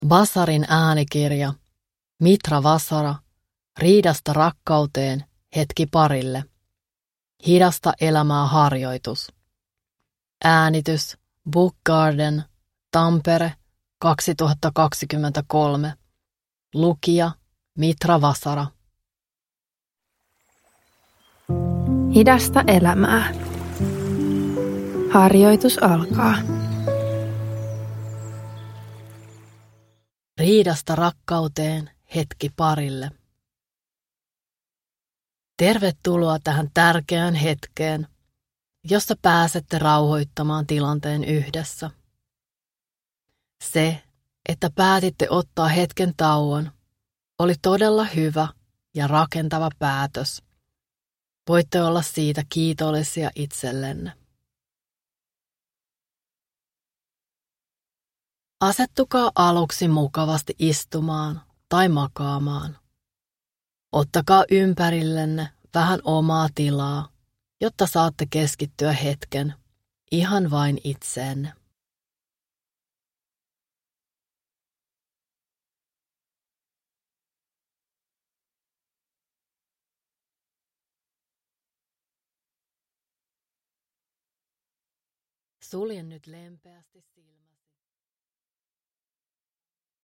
Parihetki-harjoitus